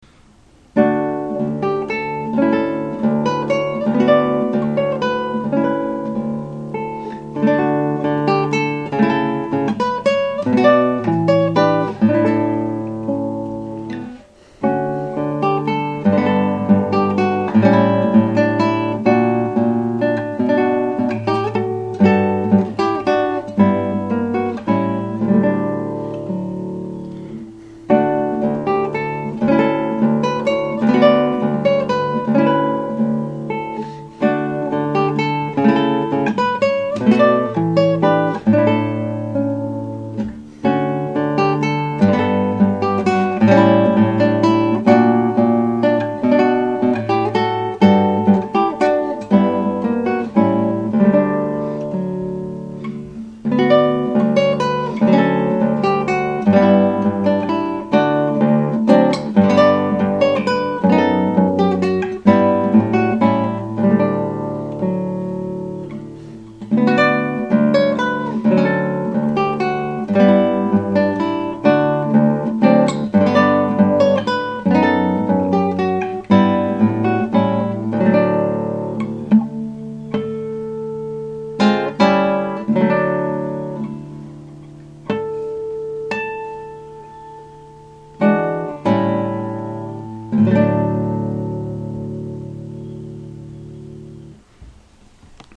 Atlanta Guitarist for Hire
One of the most beautiful works written for guitar.